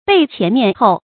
背前面后 bèi qián miàn hòu
背前面后发音